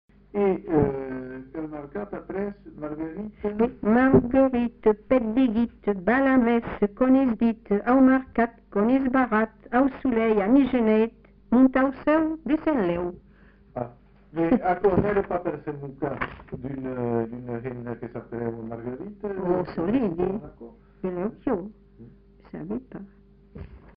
Aire culturelle : Bazadais
Lieu : Captieux
Genre : forme brève
Effectif : 1
Type de voix : voix de femme
Production du son : chanté
Classification : formulette enfantine